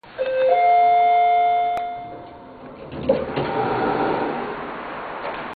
ascenseur (bientôt plus célèbre que les bruits de porte dans Doom ?) et enfin l’arrivée devant notre porte et
ascenseur.mp3